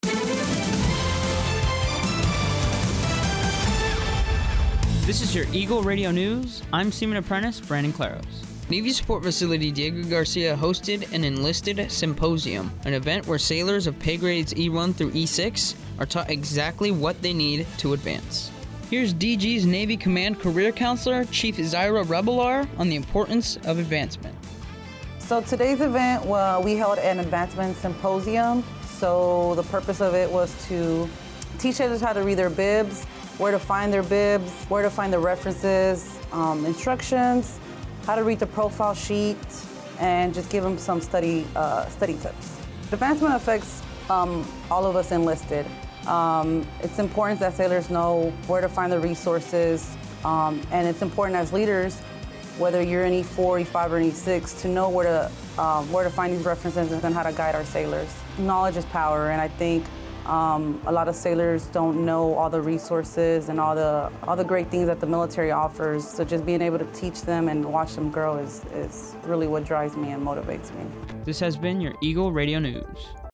Eagle Radio News is the American Forces Network Diego Garcia’s official radio newscast.